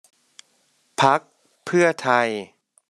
Pheu Thai Party phák phʉ̂a thai